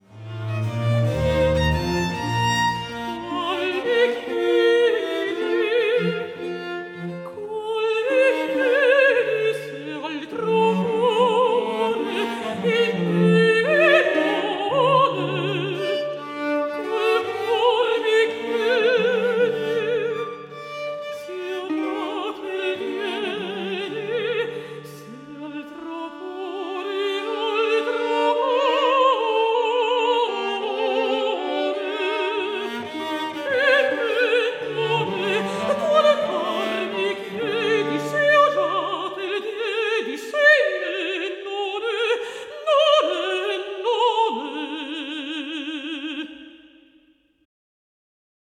ария